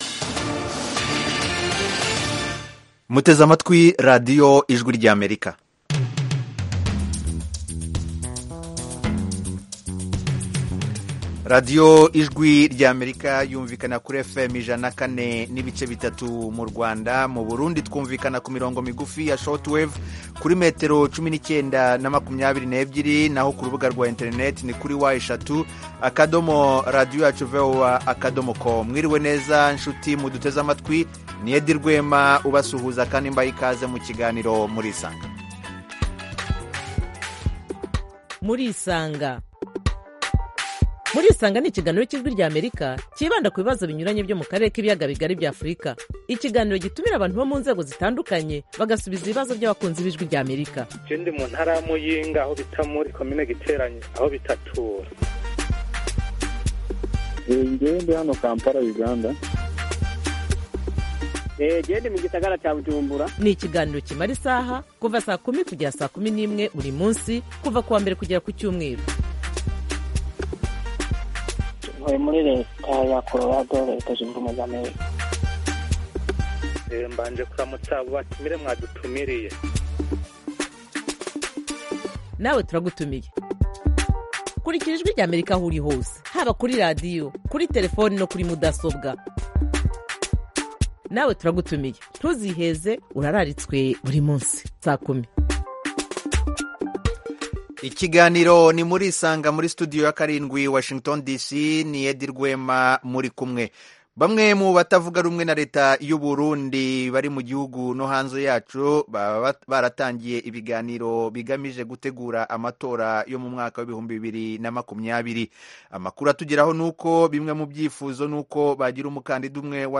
Murisanga (1400-1500 UTC): Murisanga itumira umutumirwa, cyangwa abatumirwa kugirango baganire n'abakunzi ba Radiyo Ijwi ry'Amerika. Aha duha ijambo abantu bifuza kuganira n'abatumirwa bacu, batanga ibisobanuro ku bibazo binyuranye bireba ubuzima bw'abantu.